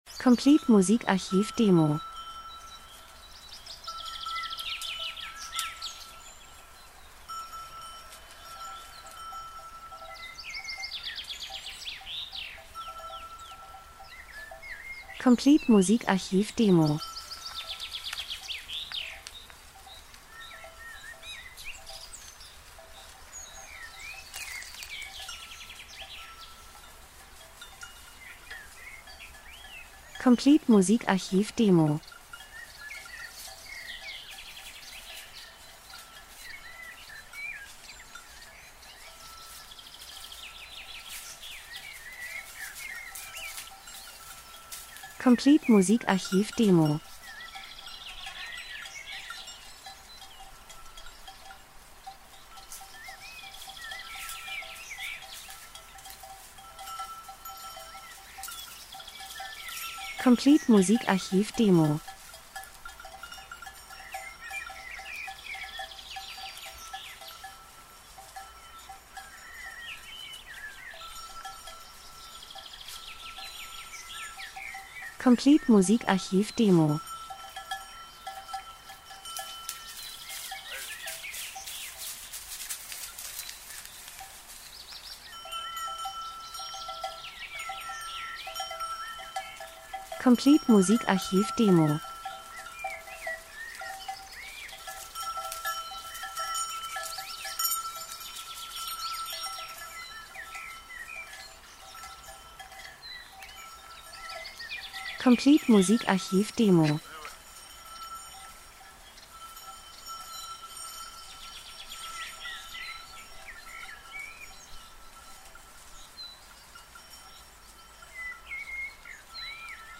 Frühling -Geräusche Soundeffekt Natur Vögel Wind Alm 03:10